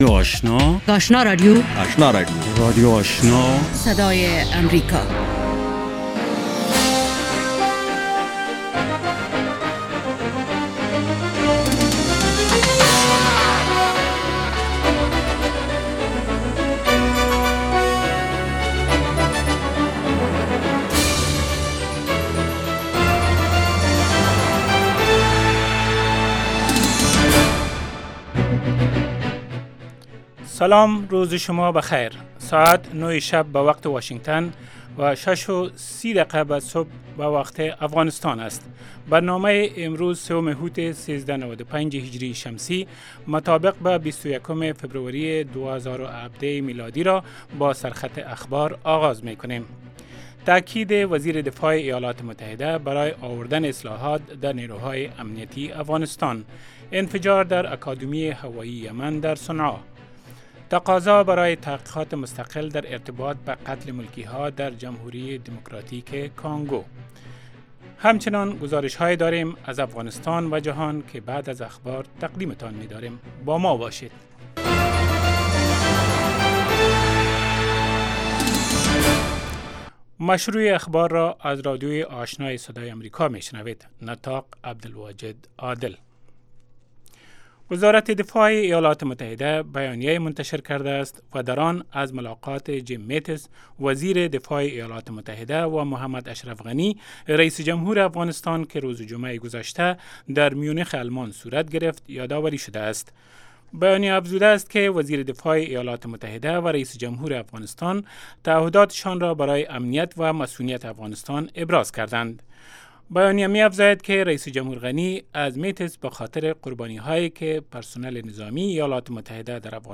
دومین برنامه خبری صبح، حاوى تازه ترين خبرهاى افغانستان و جهان است. این برنامه، همچنین شامل گزارش هایی از افغانستان، ایالات متحده امریکا و مطلب مهمی از جهان می باشد. پیش گویی وضع هوای افغانستان و چند رویداد ورزشی از جهان نیز شامل این برنامه است.